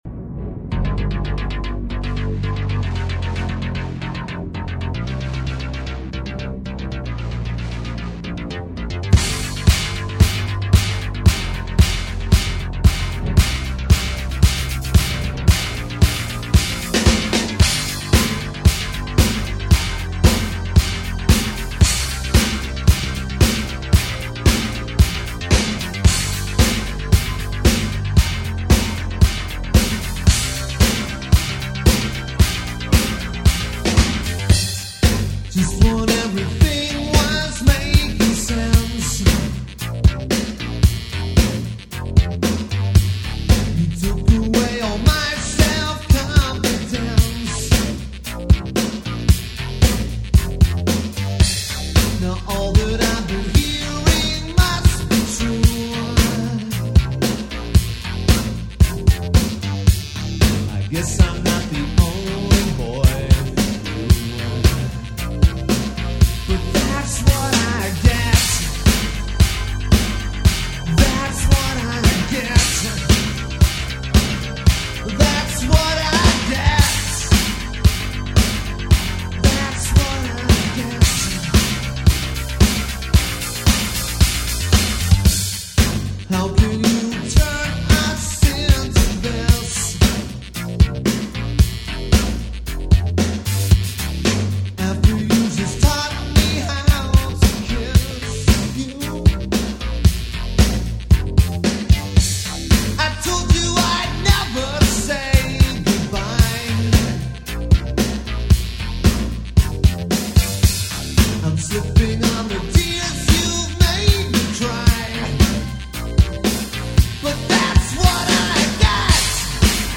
This is an interesting demo
with a very dated 80’s sound